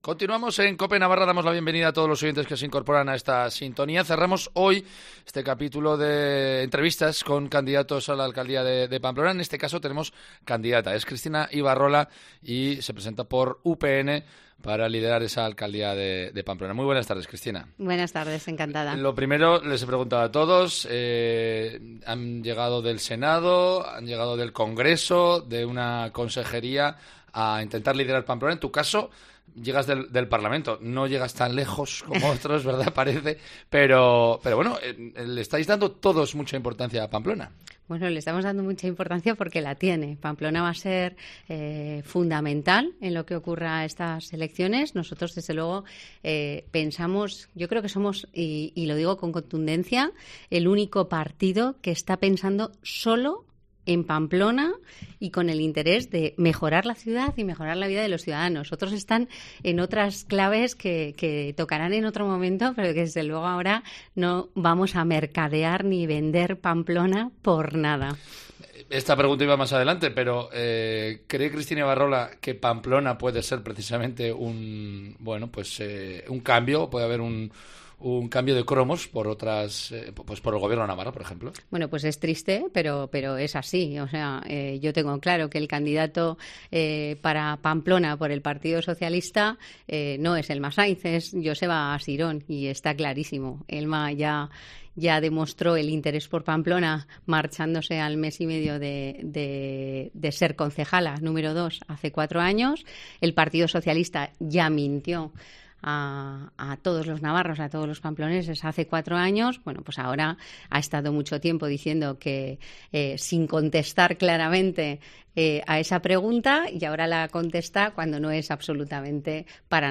Cristina Ibarrola, candidata de UPN a la alcaldía de Pamplona, presenta sus propuestas en Cope Navarra.